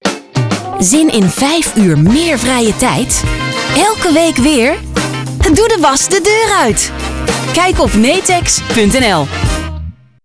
■ Radiocommercials uitgezonden op BNR Radio vanaf 13 juni 2019: